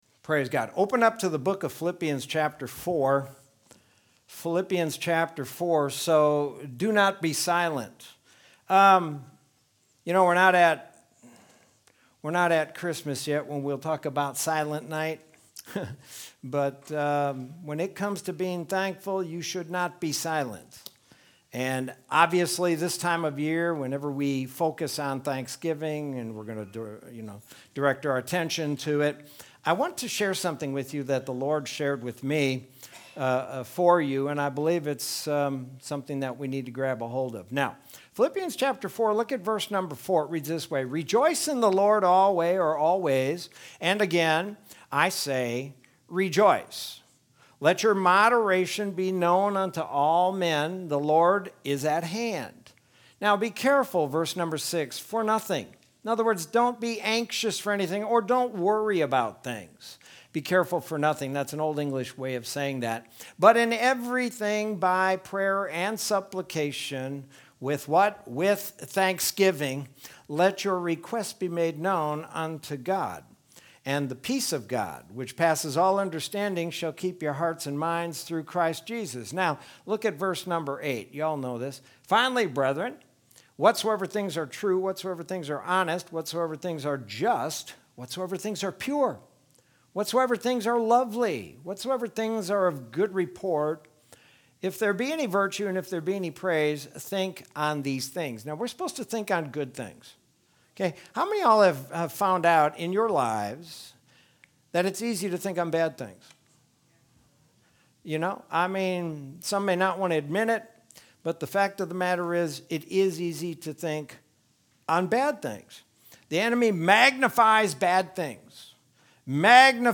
Sermon from Wednesday, November 25th, 2020.